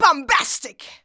BOMBASTIC.wav